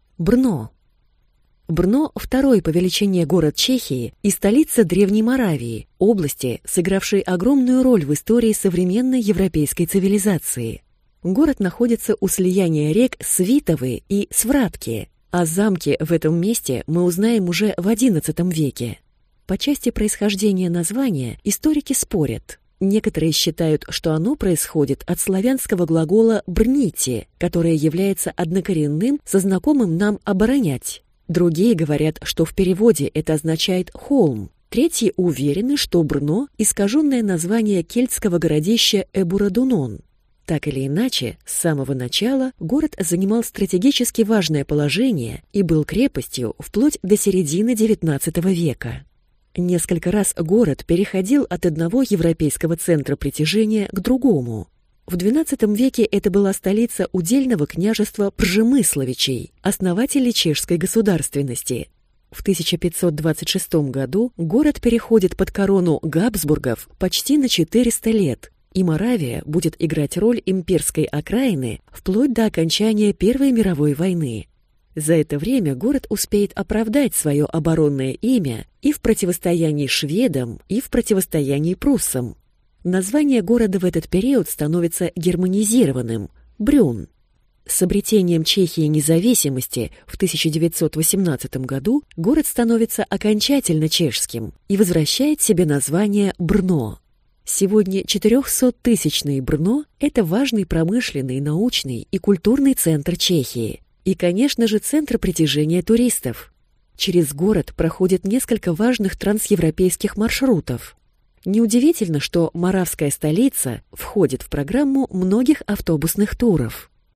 Аудиокнига Брно. Аудиогид | Библиотека аудиокниг